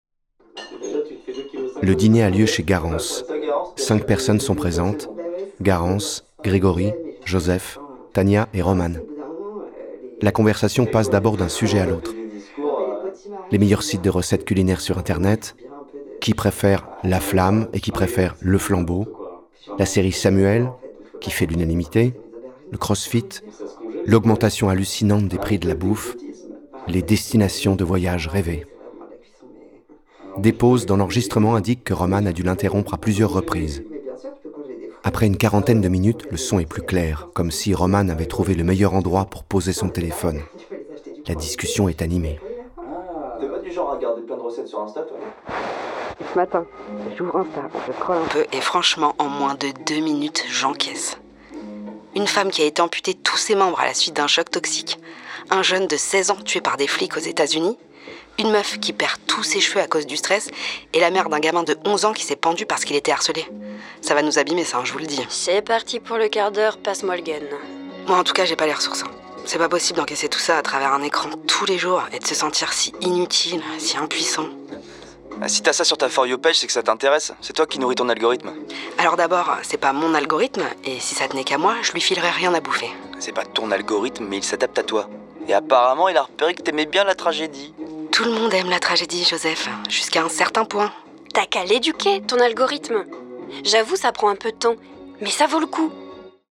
« Je suis Romane Monnier » de Delphine de Vigan, lu par 9 comédiens